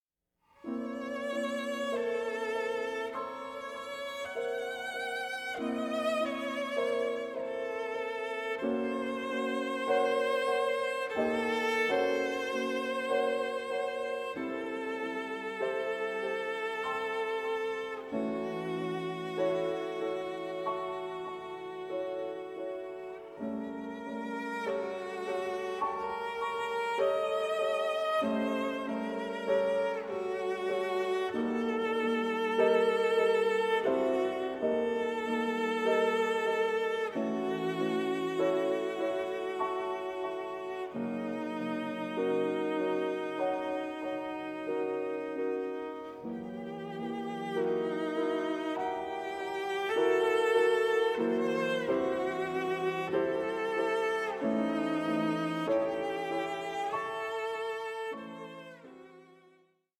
live recording
cellist